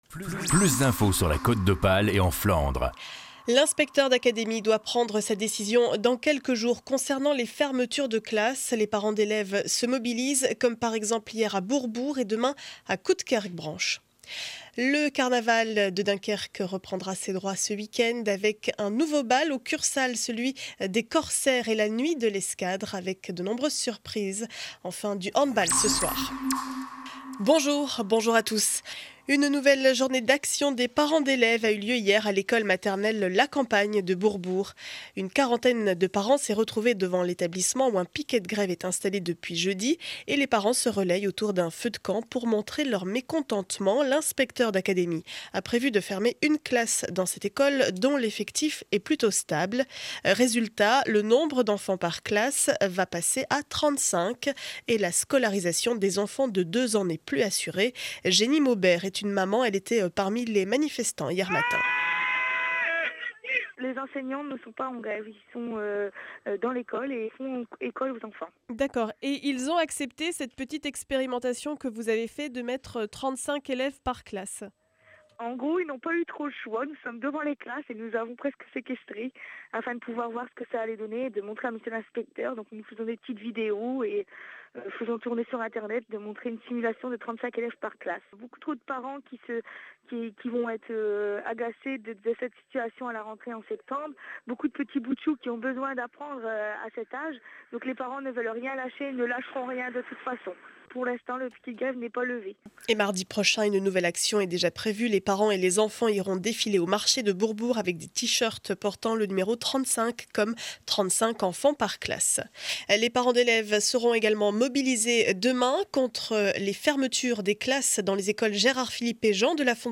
Journal du mercredi 08 février 2012 12 heures édition du Dunkerquois.